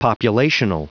Prononciation du mot populational en anglais (fichier audio)
Prononciation du mot : populational
populational.wav